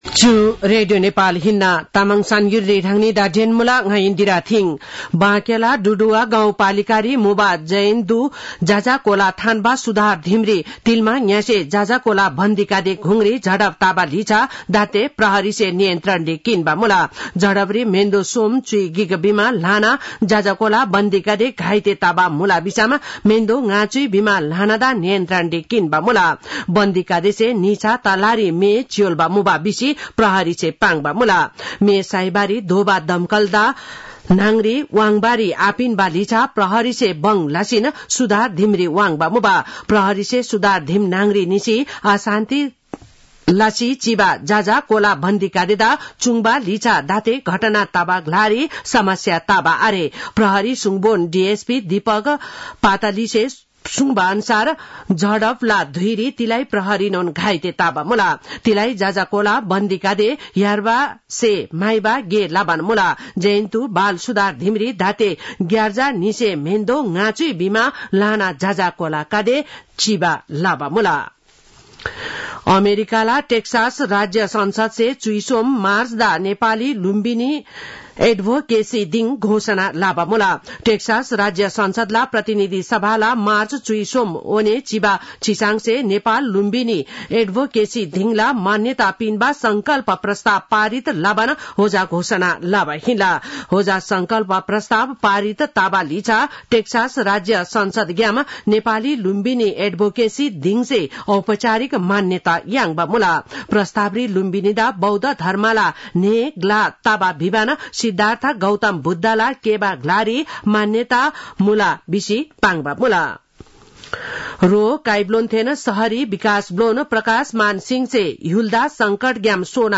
तामाङ भाषाको समाचार : २ चैत , २०८१